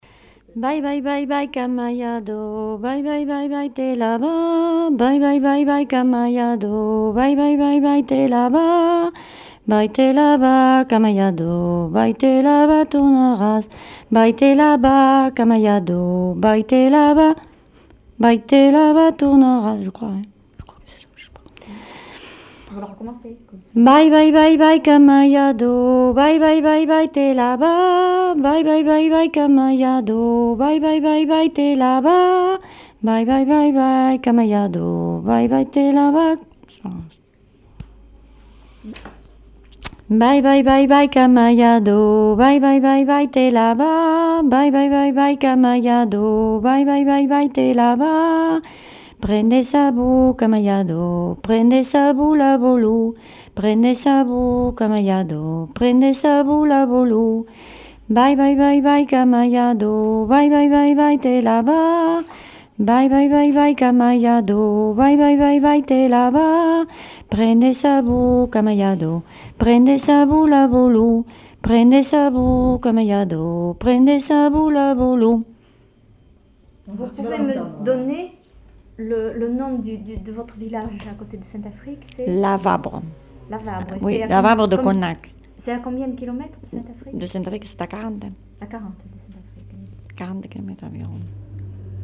Lieu : Lodève
Genre : chant
Effectif : 1
Type de voix : voix de femme
Production du son : chanté
Danse : bourrée